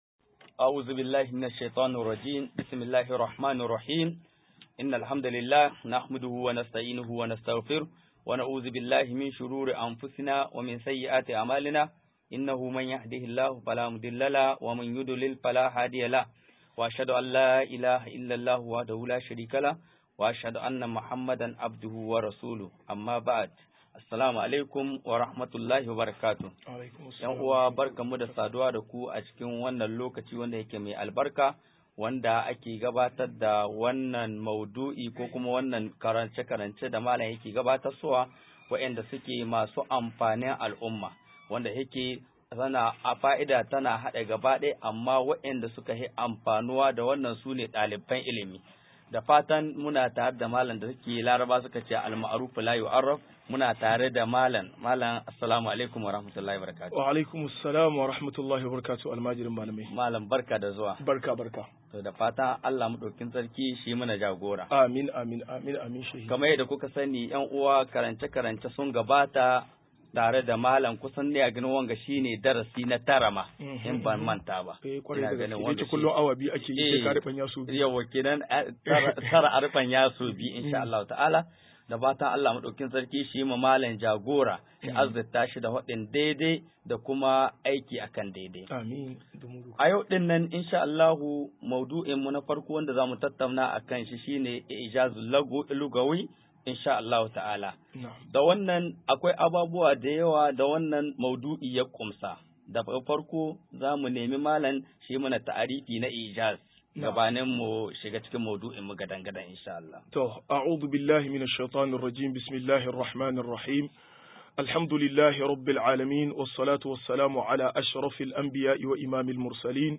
184-Mu u jizar Alkur a ni Ta Lugah - MUHADARA